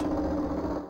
Percussion (Goblin).wav